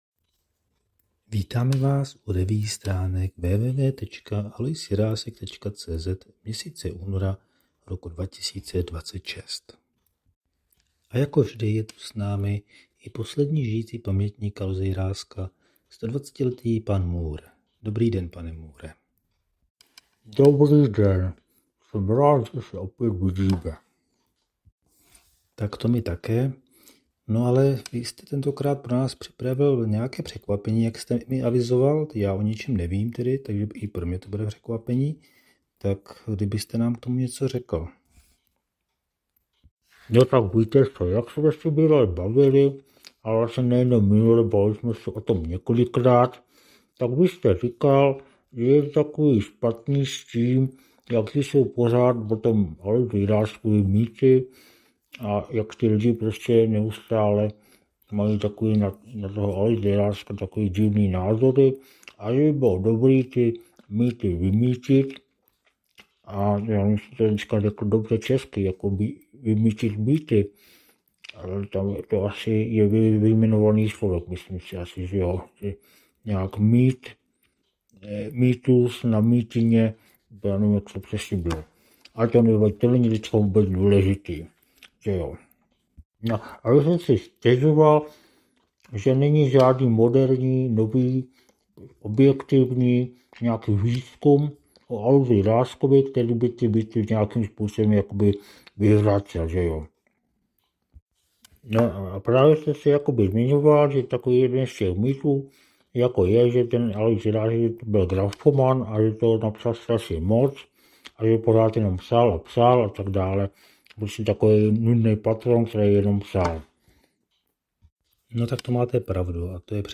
Další pokus o zvukovou revue si s obvyklou humornou nadsázkou bere na mušku jeden z tradovaných předsudků o Aloisu Jiráskovi – a sice povědomí o něm jako o grafomanovi, který jen psal a psal a psal…